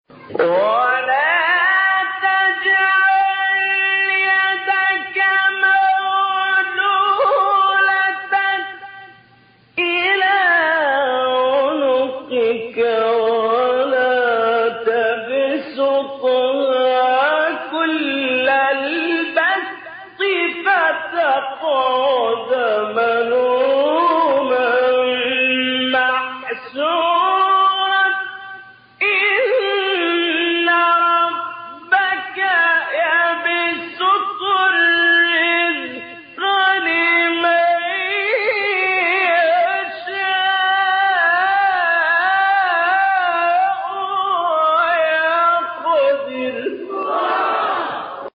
گروه شبکه اجتماعی: مقاطعی صوتی با صوت محمد اللیثی را که در مقام‌های مختلف اجرا شده است، می‌شنوید.
به گزارش خبرگزاری بین المللی قرآن(ایکنا) پنج فراز صوتی از سوره مبارکه اسراء با صوت محمد اللیثی، قاری برجسته مصری در کانال تلگرامی قاریان مصری منتشر شده است.
مقام حجاز